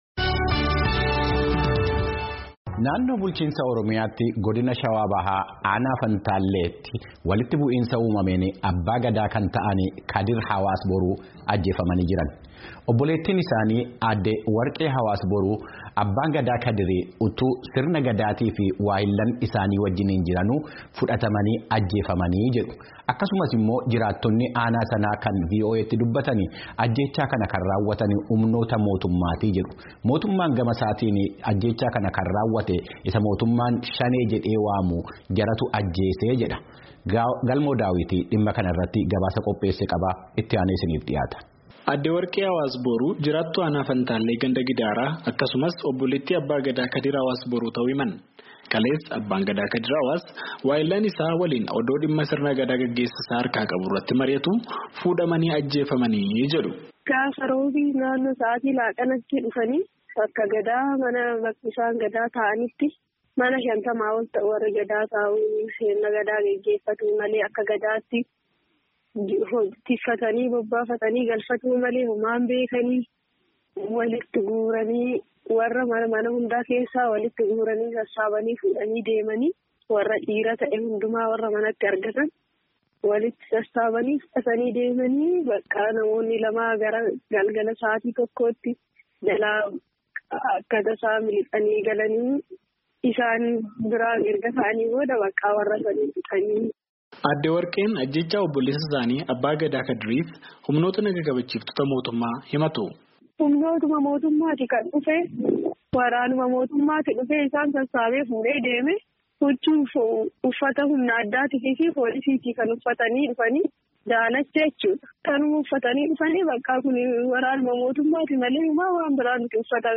adaamaa irraa gabaase.